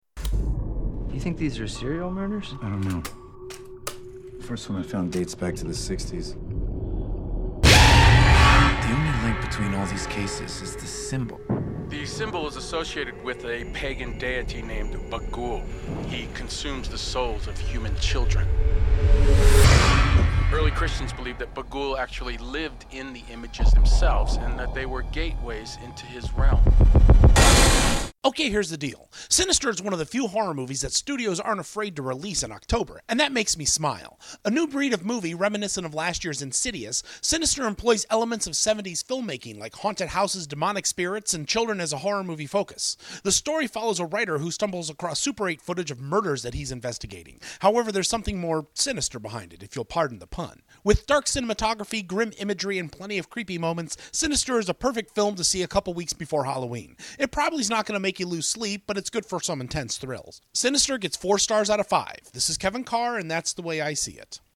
Movie Review: ‘Sinister’